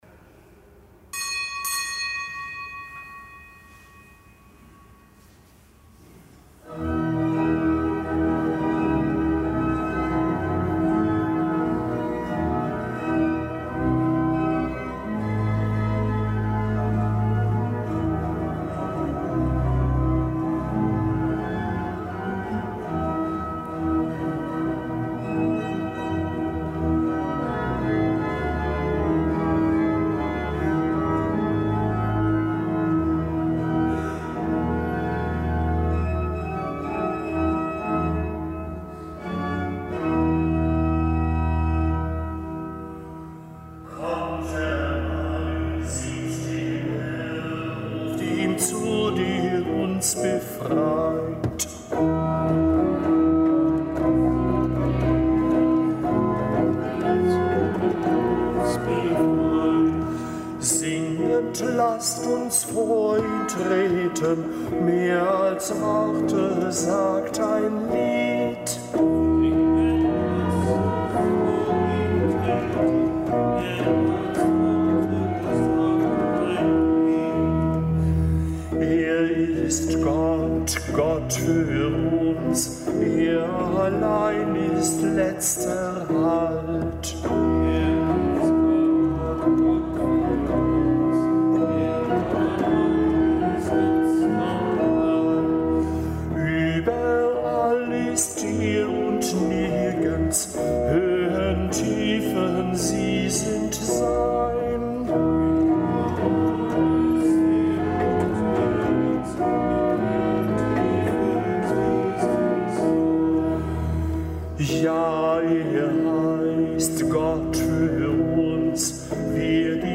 Kapitelsmesse am Freitag der siebten Woche im Jahreskreis
Kapitelsmesse aus dem Kölner Dom am Freitag der siebten Woche im Jahreskreis.